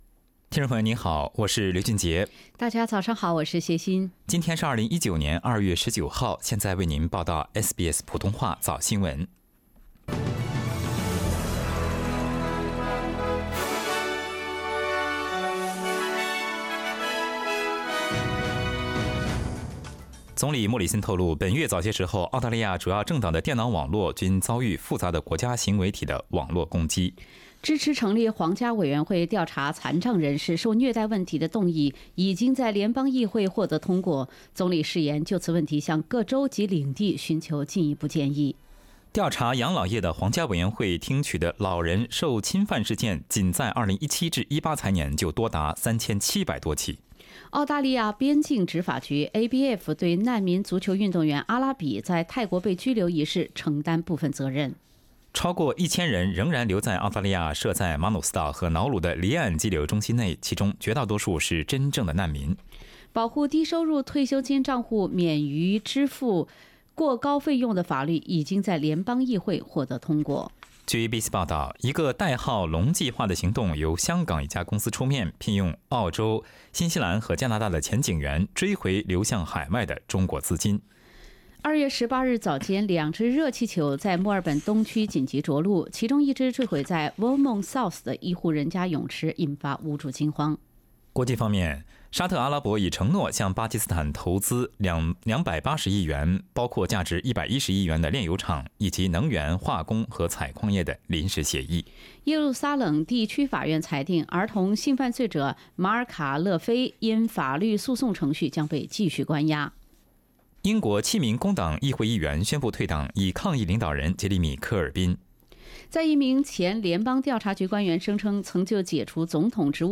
SBS 早新闻 （02月19日）
SBS Chinese Morning News Source: Shutterstock